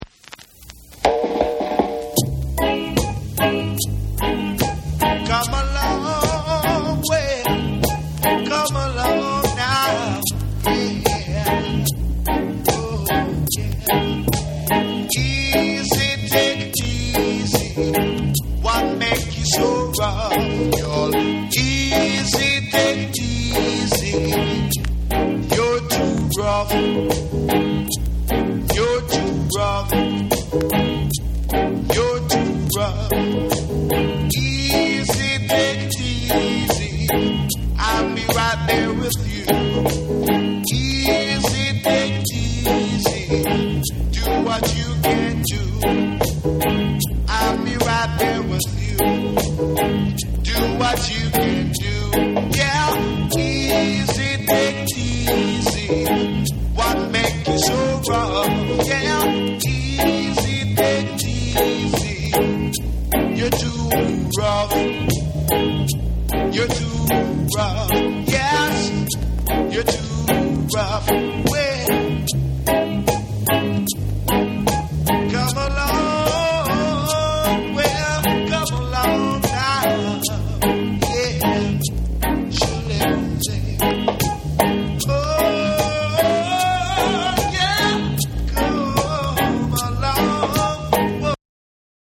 マイナーコードのルーツ・トラックに激渋ヴォーカルが展開したキラー・ナンバー！※ジャマイカ盤特有のチリノイズはいります。
REGGAE & DUB